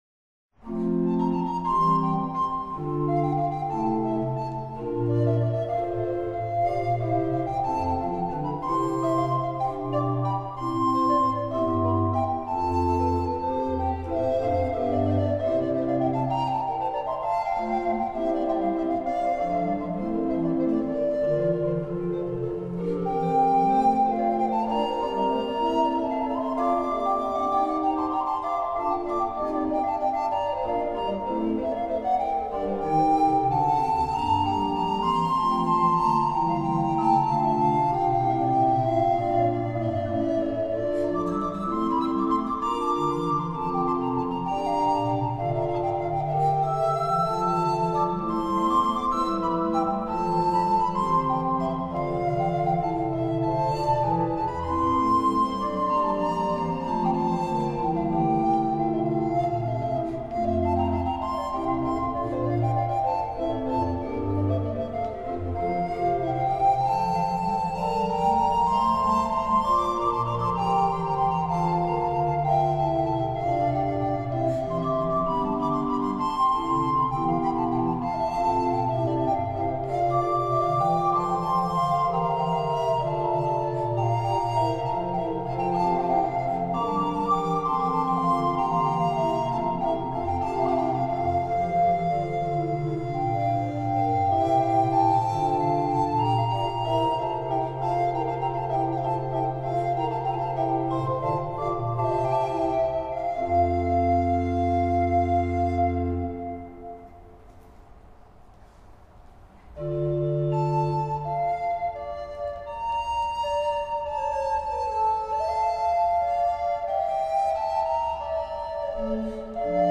Ter herinnerring aan het 60 jarig jubileumconcert van leerlingen van het Driestarcollege in de grote kerk te Gouda
14- Allegro, adagio, en vivace triosonate
18-allegro-adagio-en-vivace-triosonate.wma